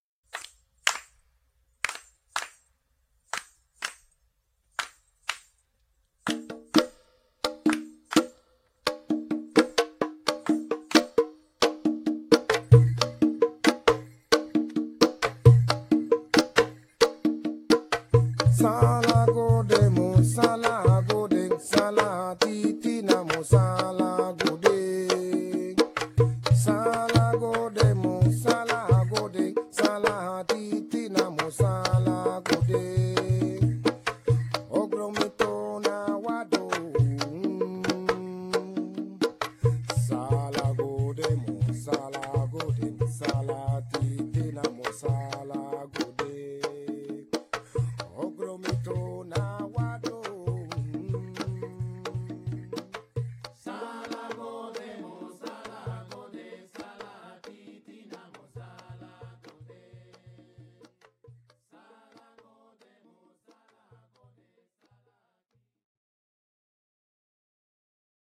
vocals, alto saxophone, bata drum, congas
keyboards
bass
drums